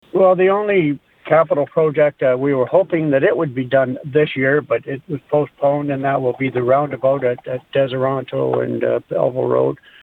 We talked with Tyendinaga Township Reeve Rick Phillips about the challenges and victories of 2020.